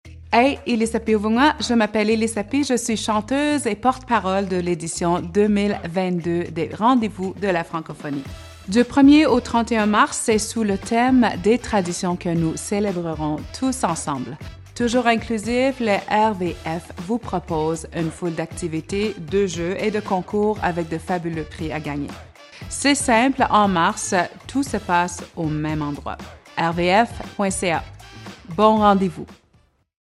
Écoutez les publicités (30 sec.) avec les co-porte-paroles qui invitent les gens à prendre part aux célébrations.
RVF_2022_Elisapie.mp3